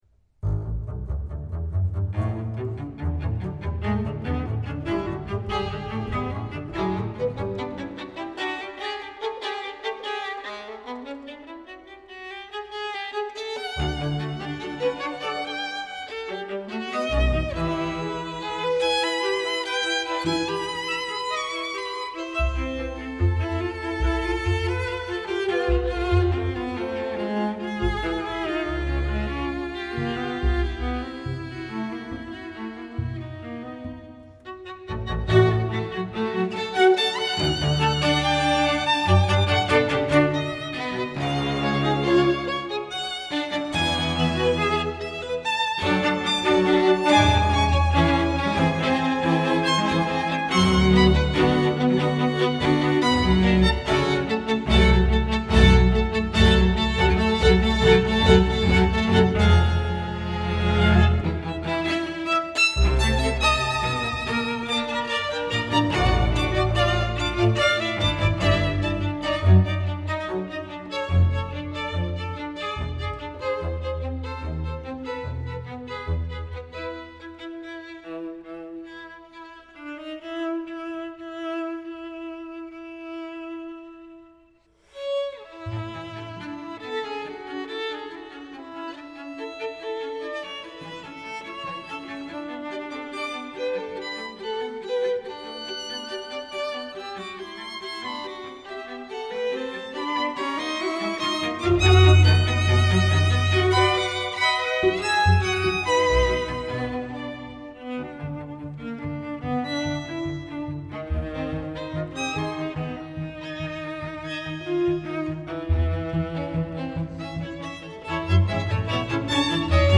double bass 2:00 2.